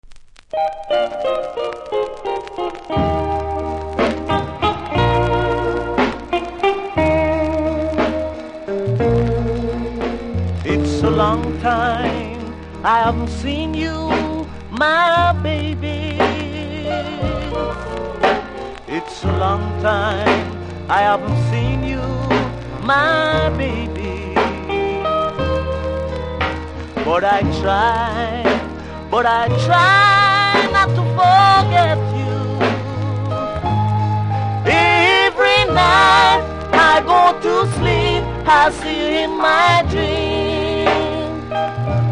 細かなキズ多めで少しノイズ感じますので試聴で確認下さい。